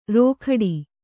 ♪ rōkhaḍi